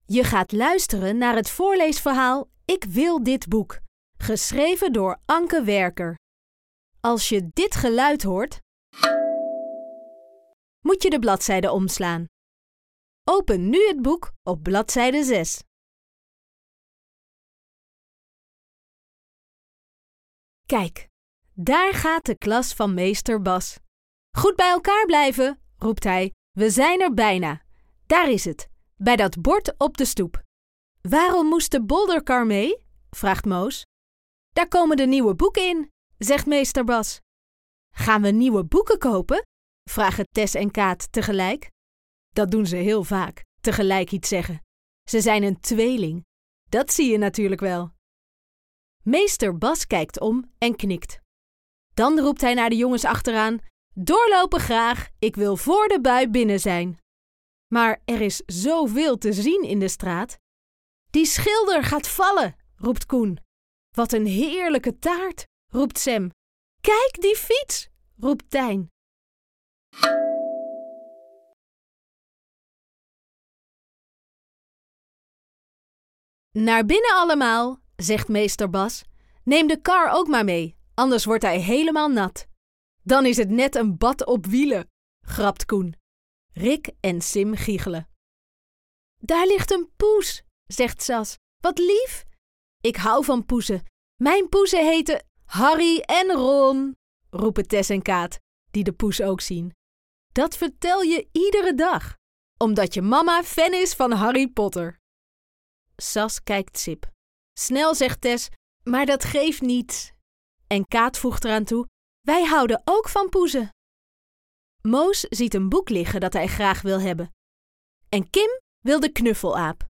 Extra woorden kern 5 en 6 Veilig leren lezen (pdf) Voorleesverhaal (mp3) Bekijk ook eens de andere boeken uit deze serie!